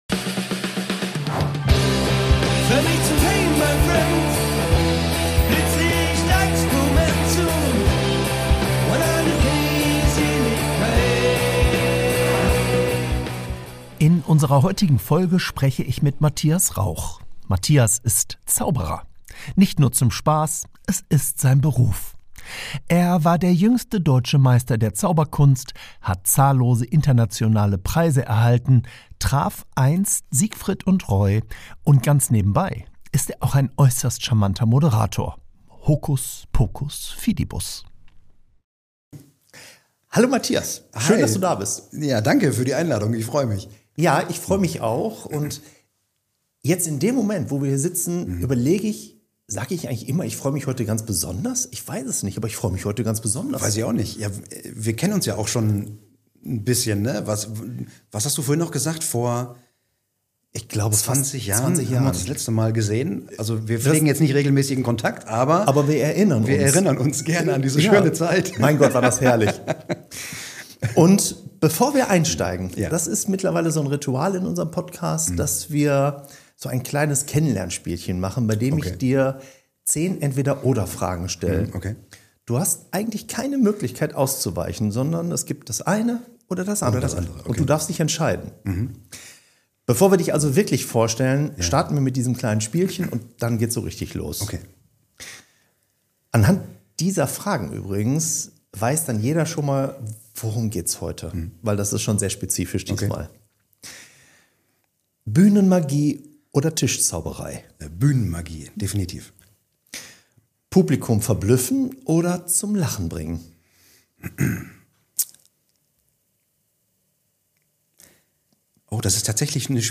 Und trotzdem klingt er im Gespräch überraschend bodenständig.